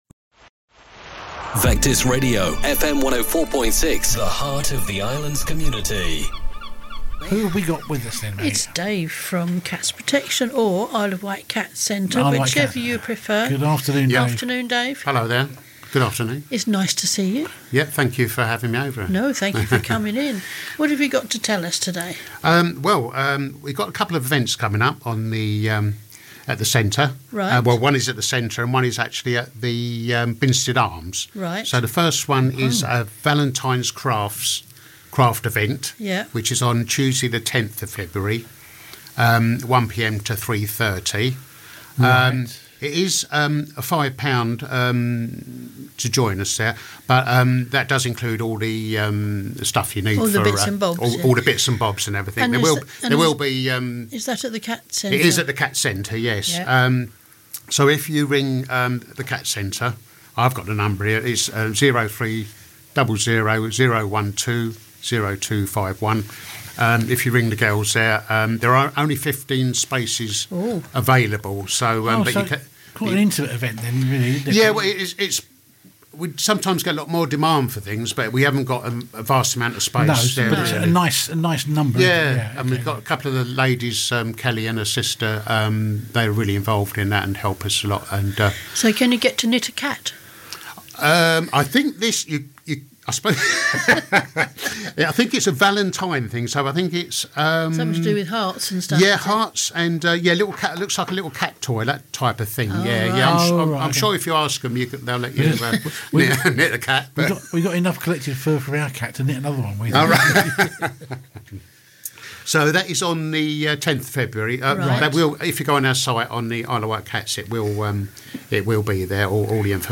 Vectis Interviews 2026